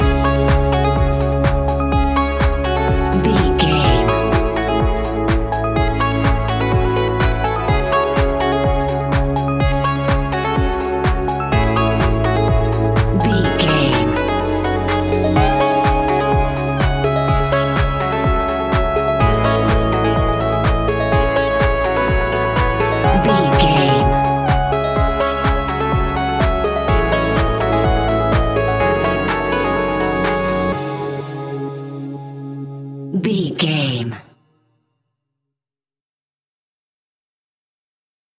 Aeolian/Minor
C#
groovy
futuristic
hypnotic
dreamy
smooth
synthesiser
drum machine
electric guitar
funky house
deep house
nu disco
upbeat
funky guitar
wah clavinet
fender rhodes
synth bass
horns